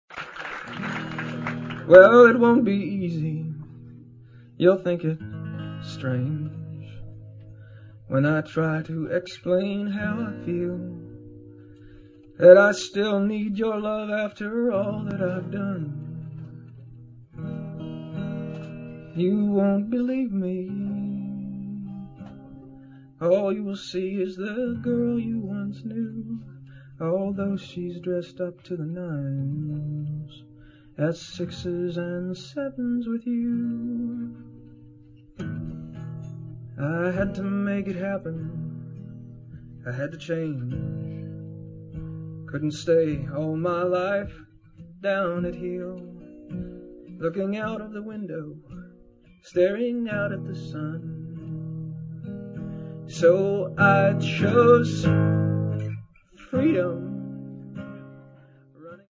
Sessions radios & lives inédits
So What, Oslo - 2000